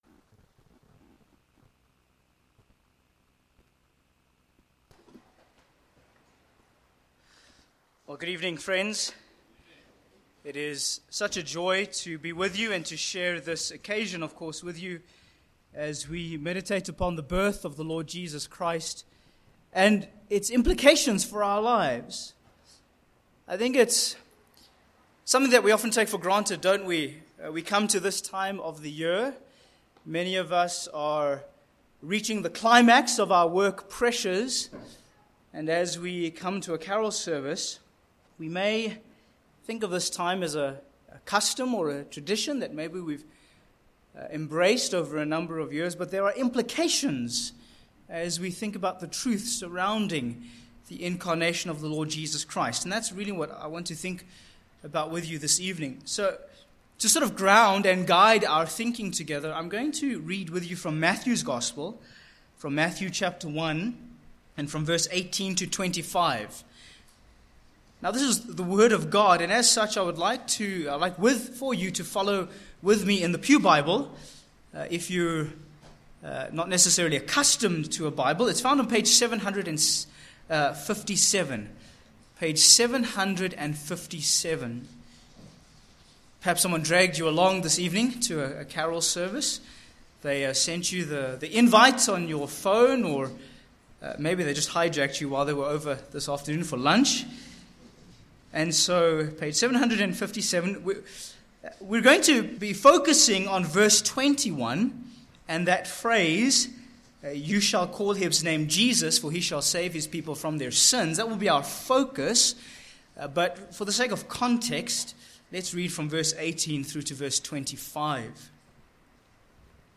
Sermon points: 1. A Divine Conception v18
Service Type: Evening